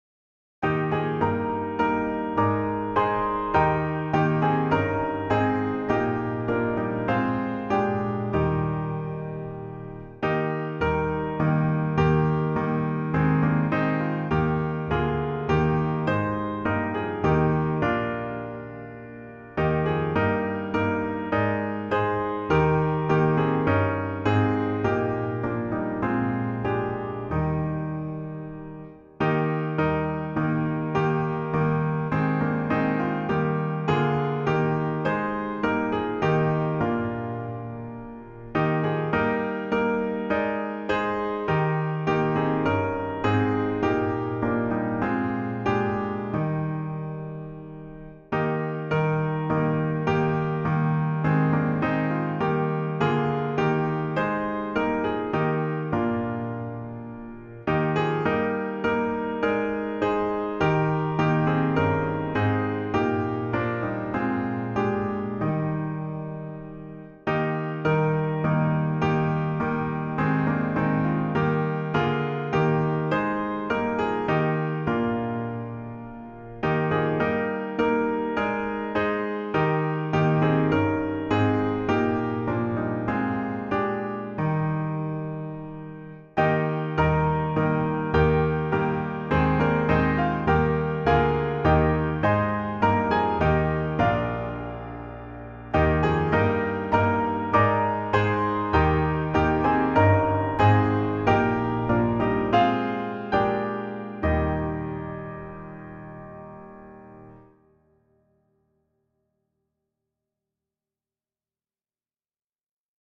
Voicing/Instrumentation: SATB , Choir Unison